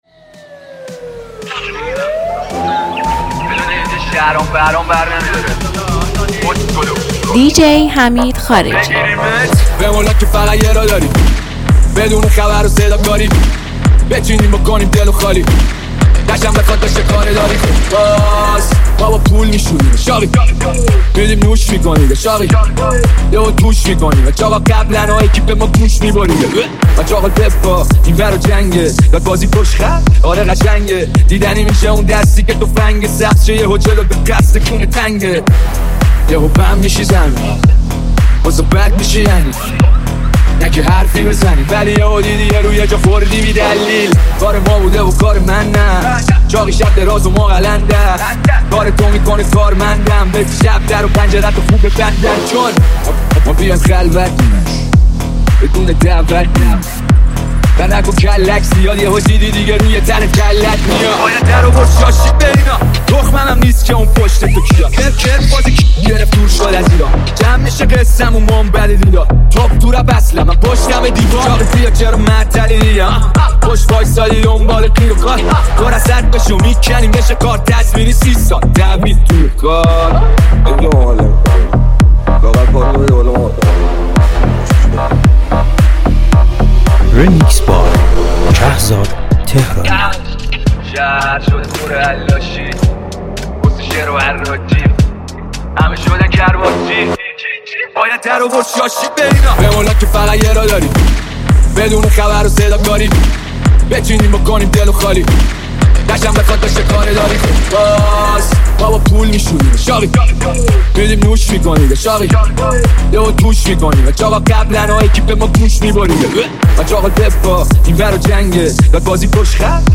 موزیک رپ
ریمیکس